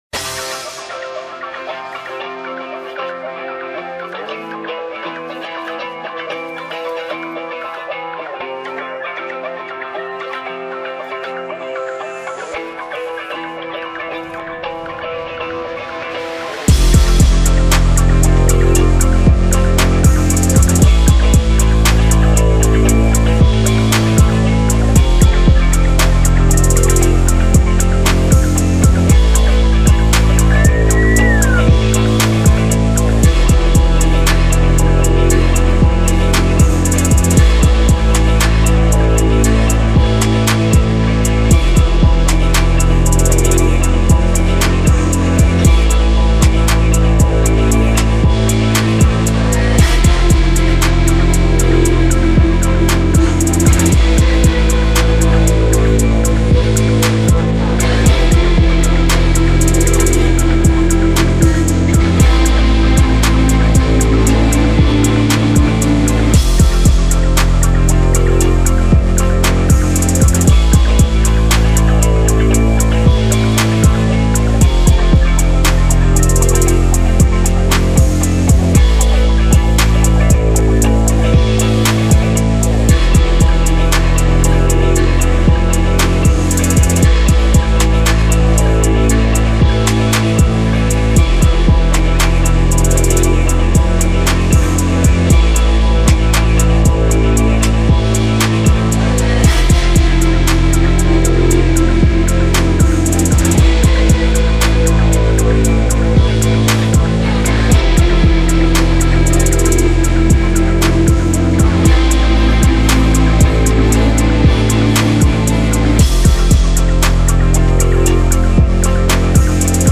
official instrumental
Rap Metal Instrumentals